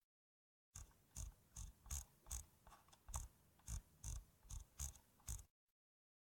Звук прокрутки колесика мыши